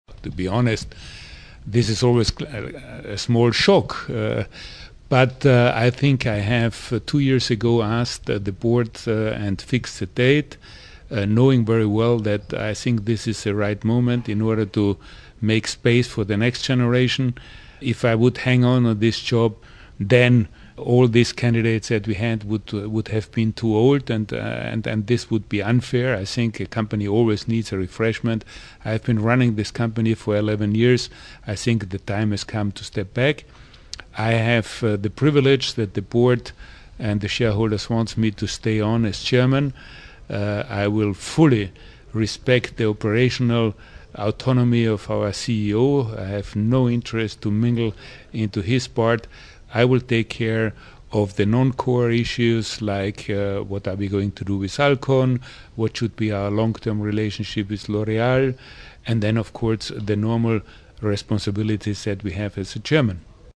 Nestlé CEO and chairman Peter Brabeck describes how he feels about stepping down as CEO in April.